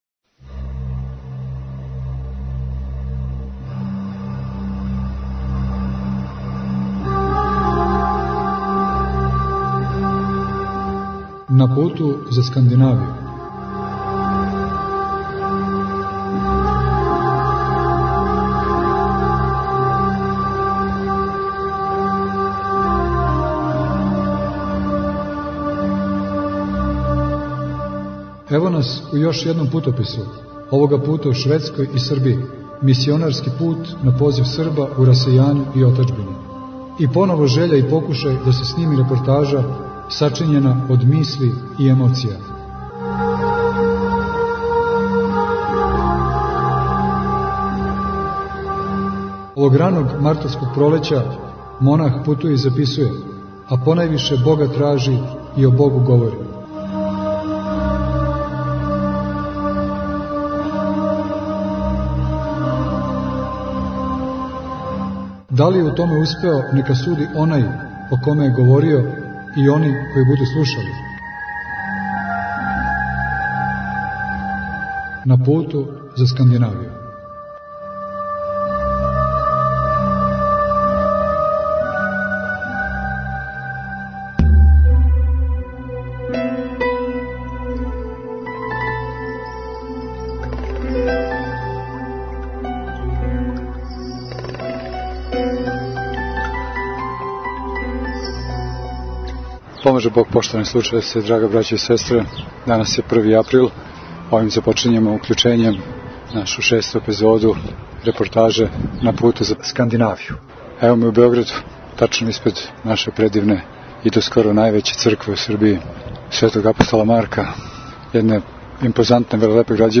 Мисионарски пут на позив Срба у расејању и отаџбини. И поново жеља и покушај да се сними репортажа сачињена од мисли и емоција. Овог раног мартовског пролећа, монах путује и записује, а понајвише Бога тражи и о Богу говори.